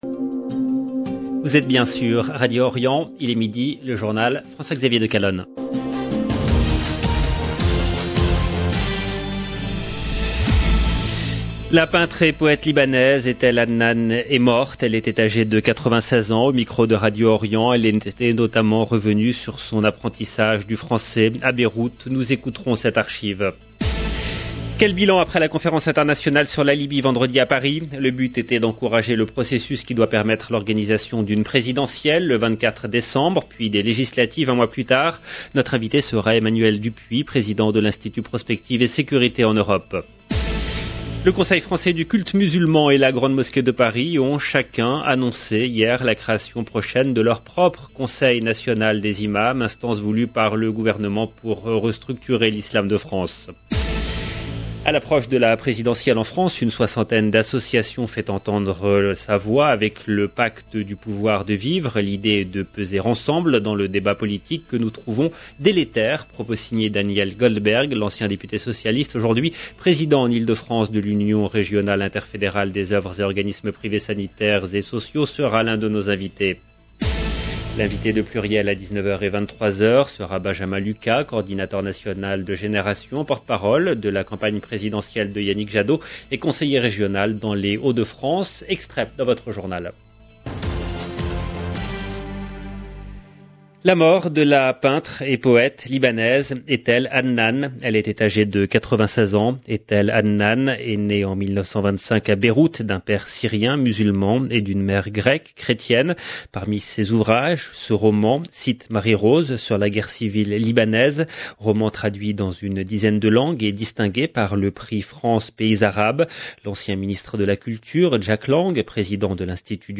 Au micro de Radio Orient, elle était notamment revenue sur son apprentissage du Français à Beyrouth. Nous écouterons cette archive. Quel bilan après la conférence internationale sur la Libye vendredi à Paris.